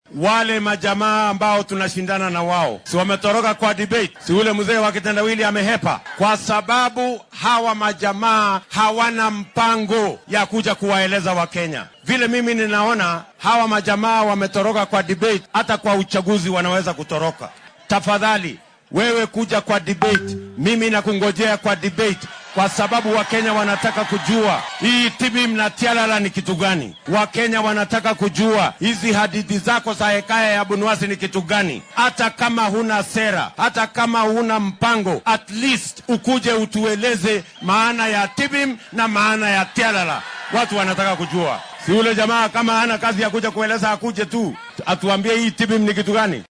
Xilli uu maanta dadweynaha kula hadlay ismaamulka Nandi ayuu William Ruto carrabka ku adkeeyay inuu iska xaadirin doono goobta lagu qabanaya doodda musharraxiinta madaxweyne.